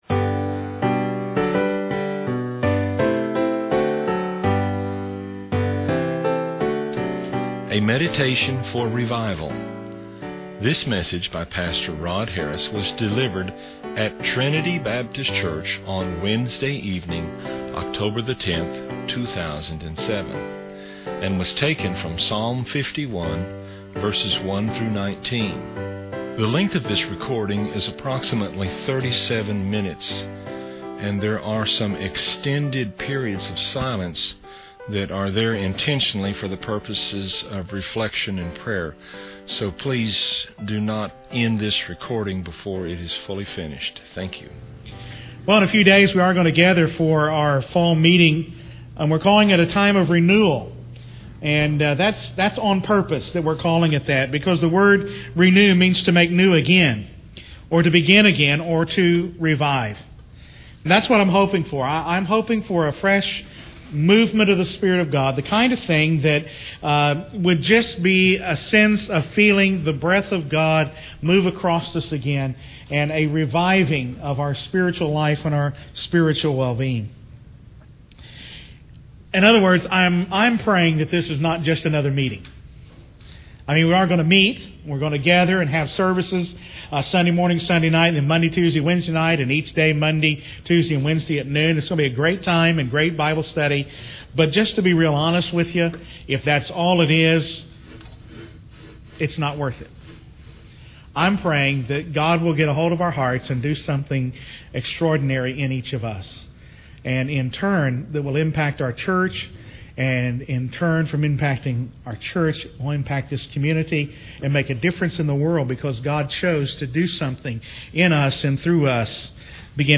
Sermons on Revival - TBCTulsa